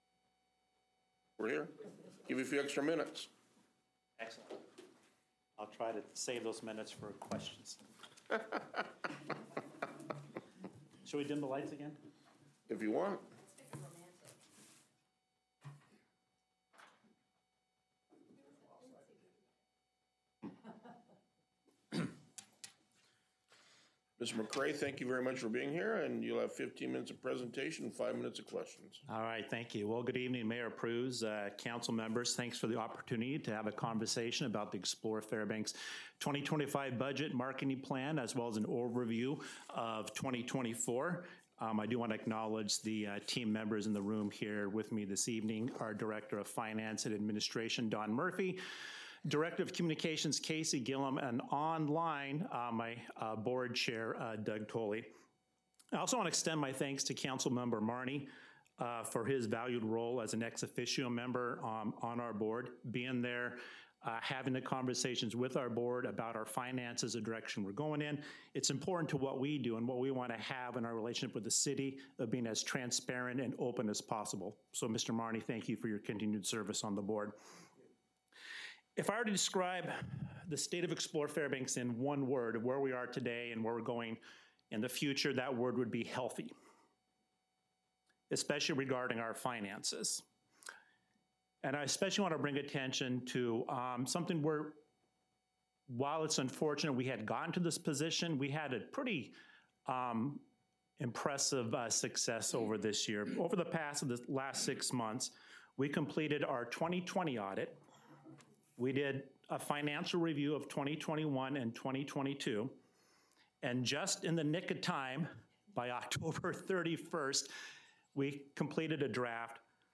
Council Work Session - Explore Fairbanks Annual Report and Presentation
A five-minute Q&A period will follow the presentation.